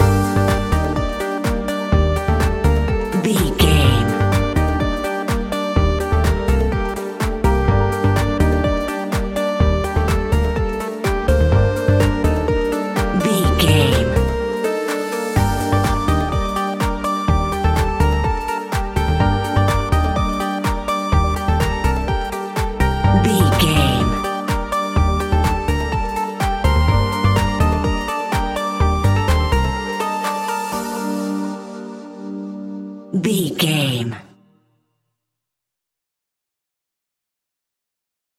Aeolian/Minor
groovy
uplifting
driving
energetic
drum machine
synthesiser
bass guitar
funky house
nu disco
upbeat